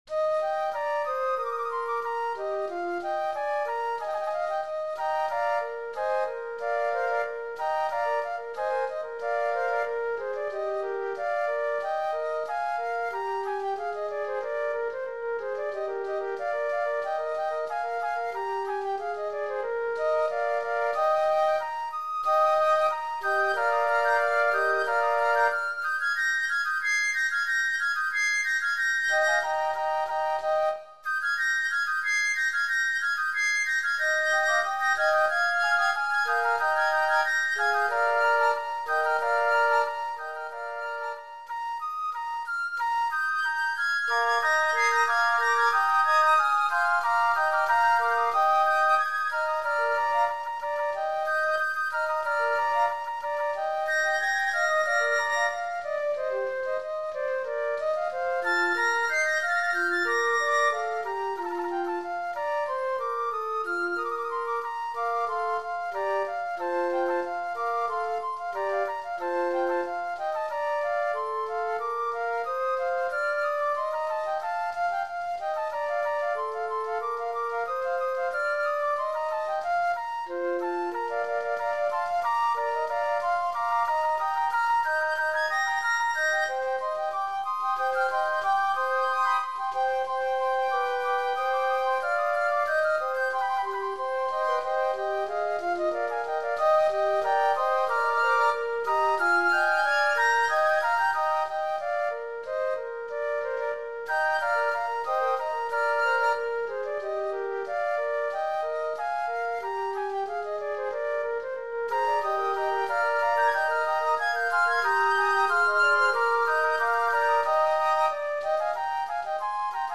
Voicing: Flute Quintet